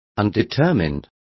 Complete with pronunciation of the translation of undetermined.